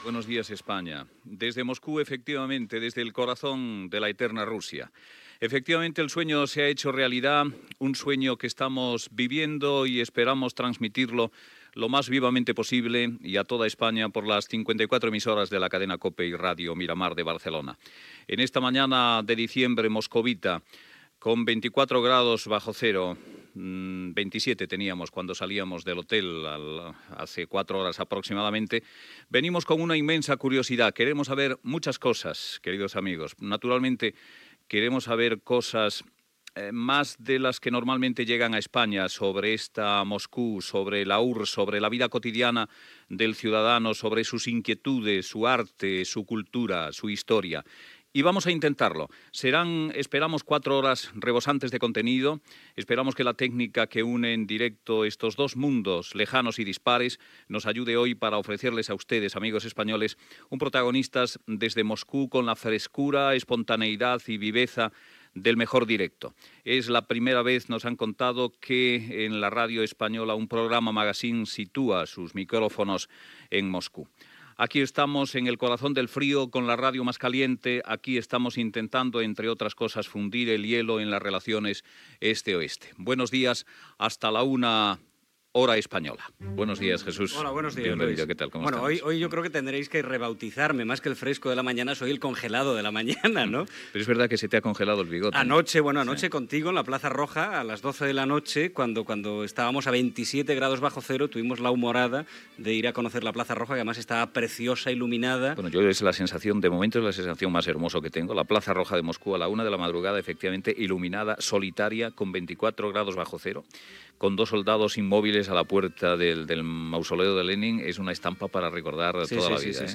Fragments diversos del programa emès des de Moscou.
Info-entreteniment